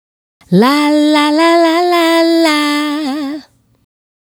La La La 110-D.wav